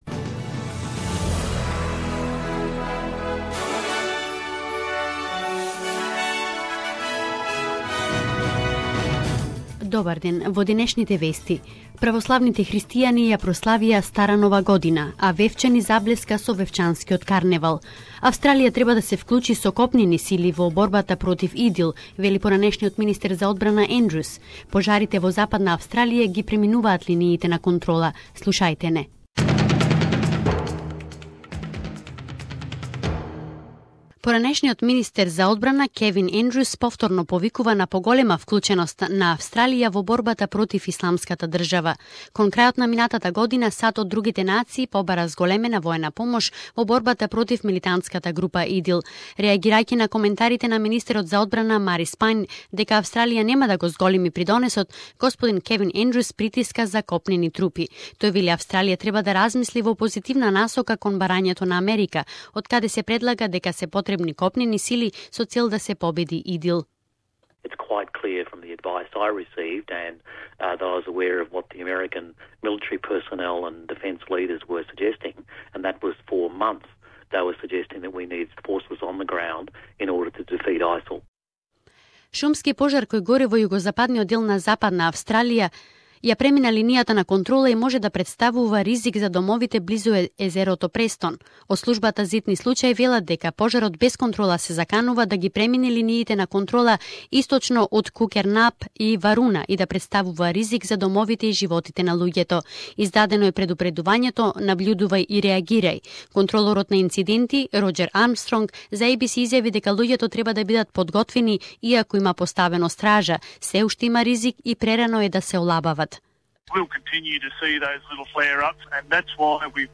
News bulletin 14.01.2016